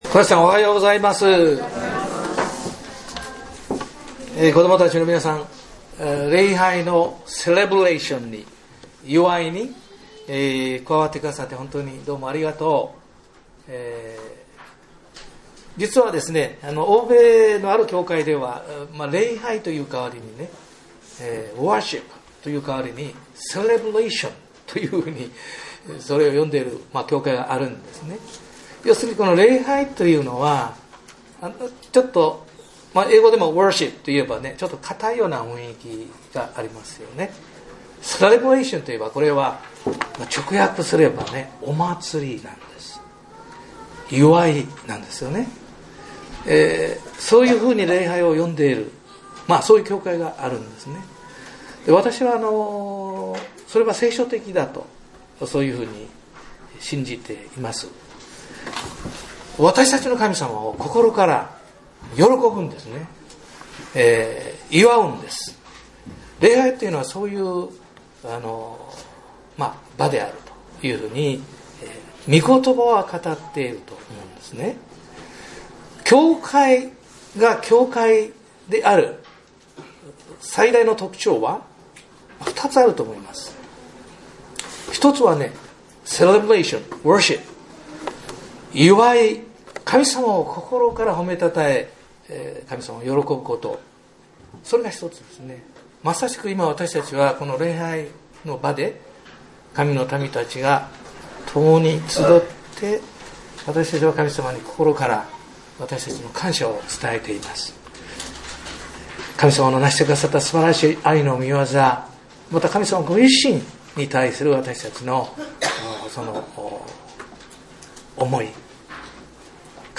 全世界におけるキリストの救いと裁き | 桑名キリスト教会